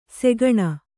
♪ segaṇa